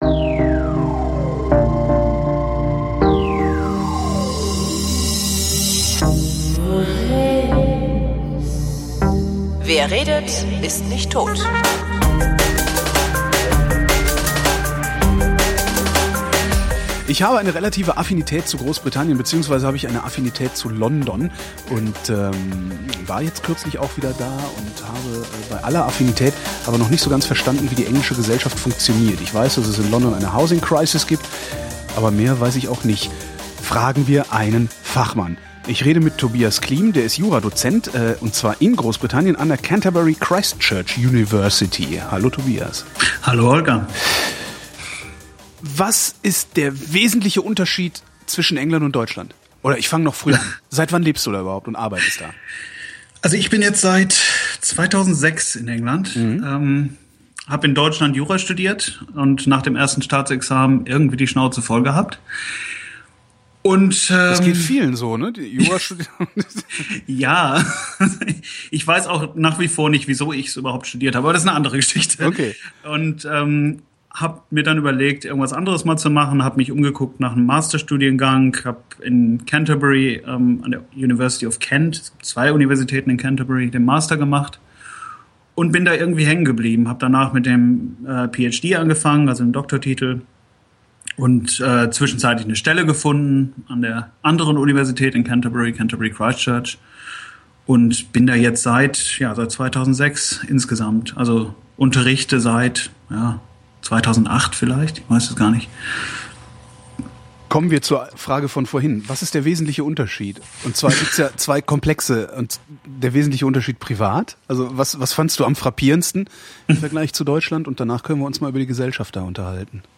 (Ich war zum Aufnahmezeitunpunkt stark erkältet.